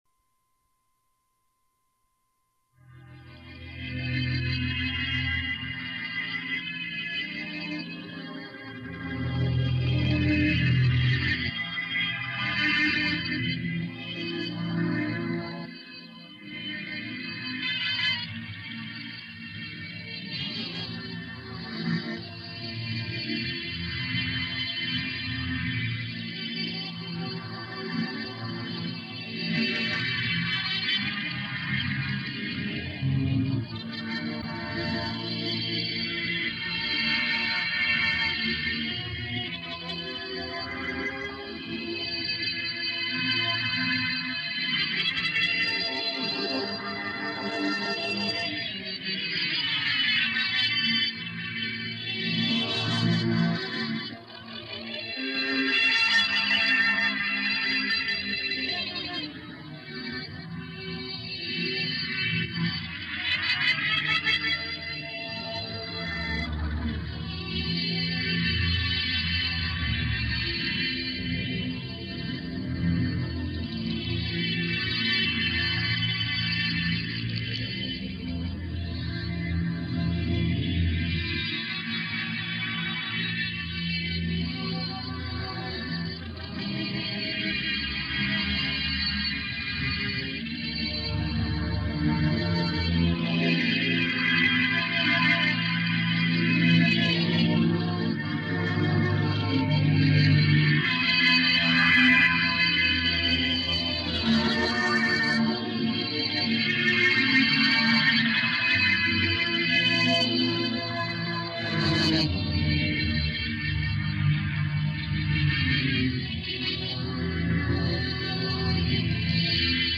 "Into the Clouds" This is an ambient electronic piece- no synthesizers, this is an electric guitar (believe it or not) and bass.
The first two pieces were originally recorded on a 1967 Ampex 8-track one-inch tape machine, then a basic mix was done with all analog gear.